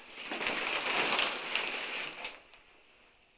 showerCurtainClose.wav